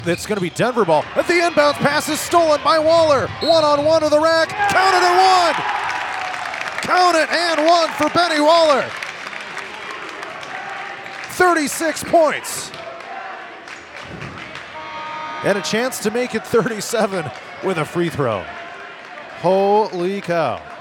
The Aplington-Parkersburg basketball teams split a tightly contested NICL-Central doubleheader at Denver Tuesday, as aired on 99 The Wave.